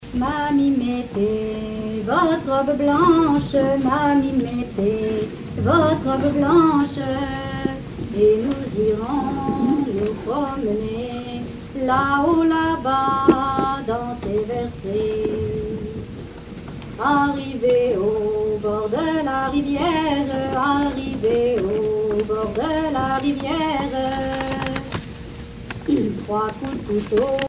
Note au folk-club Le Bourdon
Genre strophique
Pièce musicale inédite